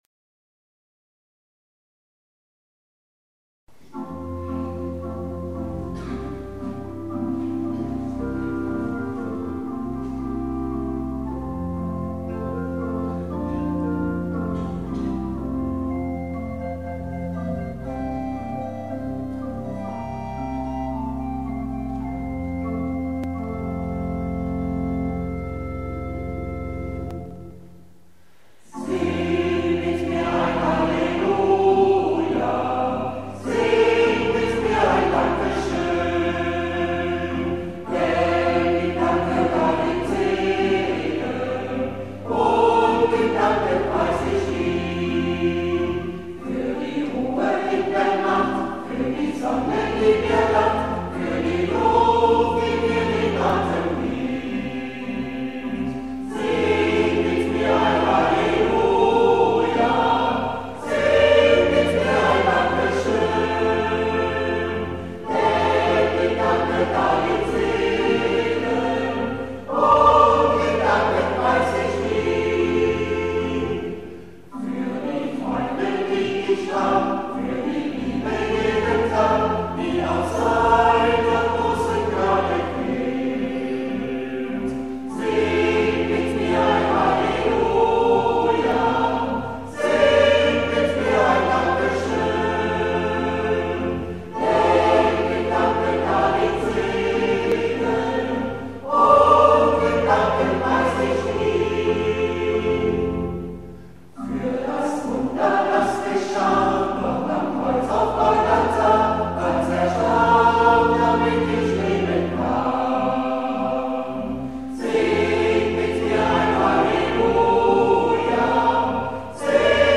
Kirchenmusikalische Andacht zum 200-jährigen Bestehen
Link auf Liedvortrag des Kirchenchores